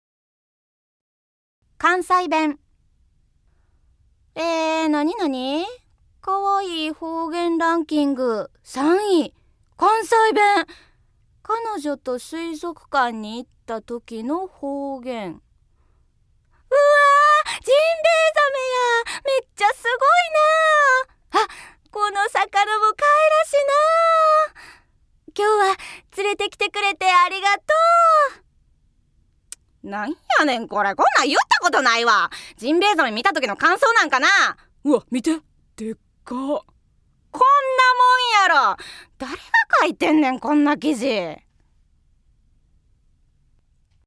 ◆関西弁◆